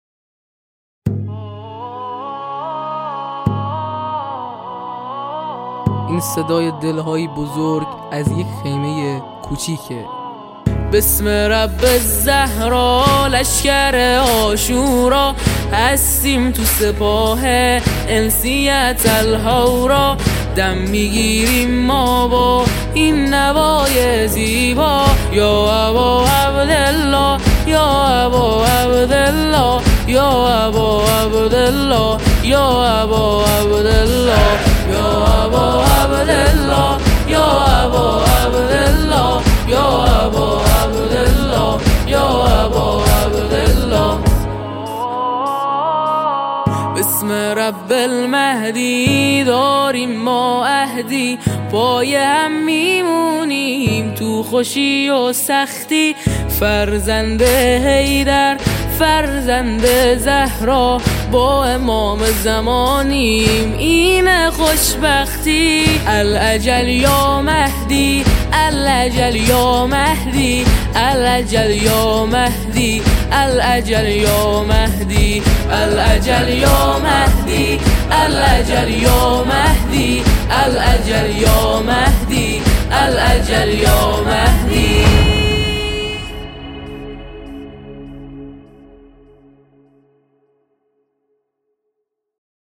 دانلود نماهنگ دلنشین